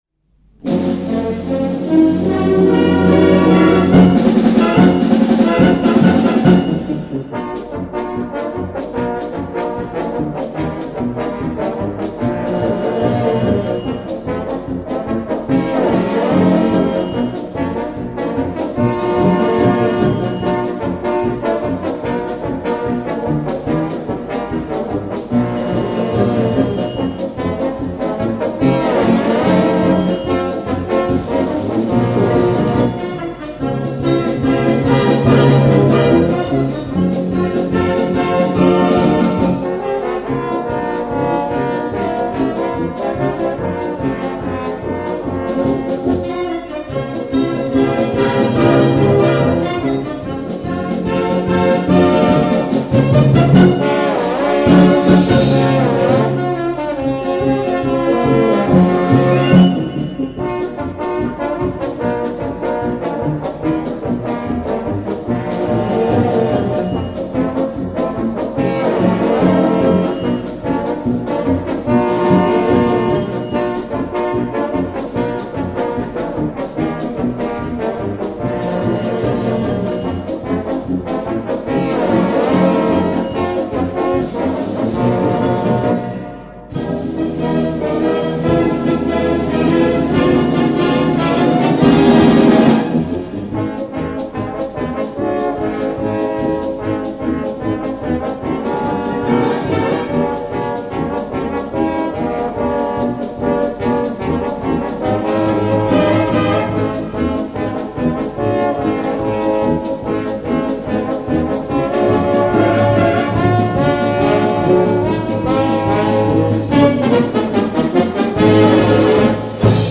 Gattung: Marsch-Dixie für 3 Posaunen
Besetzung: Blasorchester
Eine tolle Shownummer für 3 Posaunen!